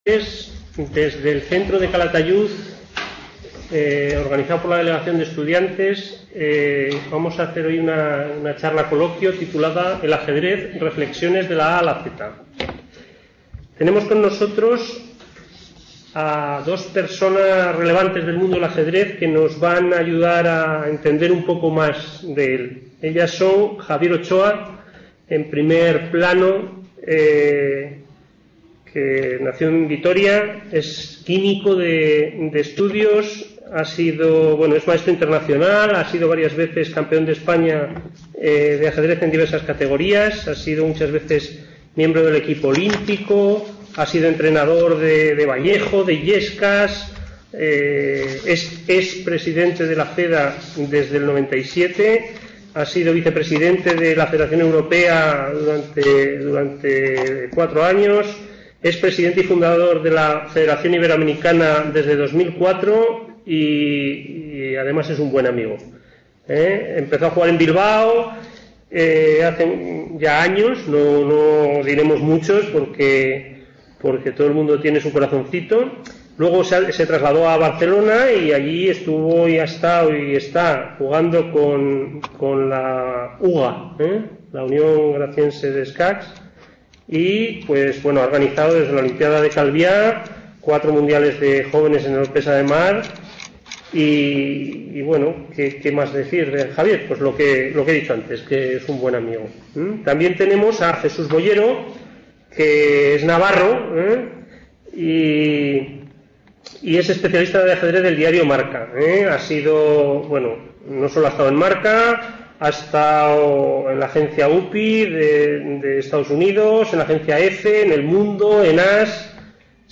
Reflexiones de la A a la Z | Red: UNED | Centro: UNED | Asig: Reunion, debate, coloquio...